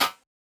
Keyflo Prince Style Perc 1.wav